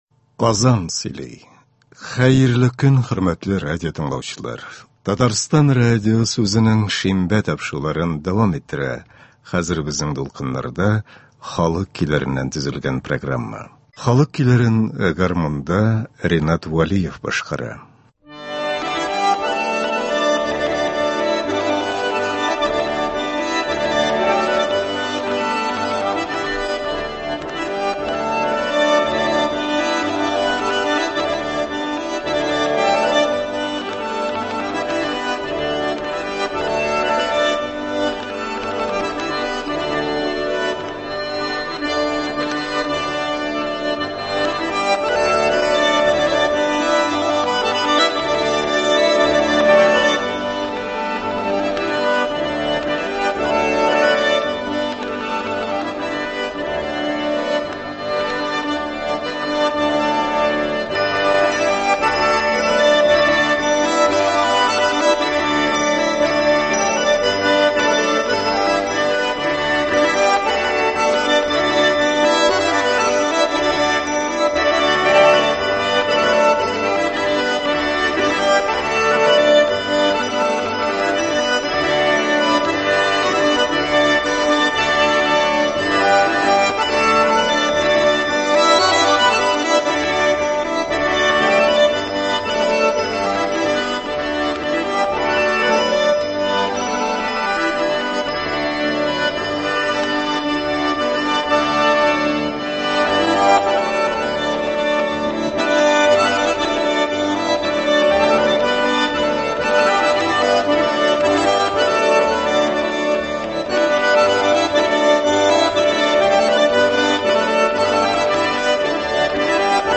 Татар халык көйләре (18.06.22)
Бүген без сезнең игътибарга радио фондында сакланган җырлардан төзелгән концерт тыңларга тәкъдим итәбез.